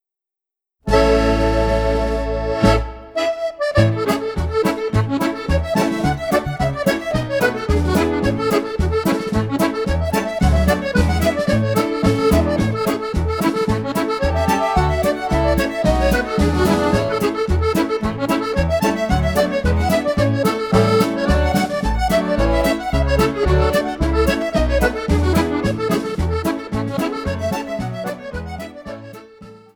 Reel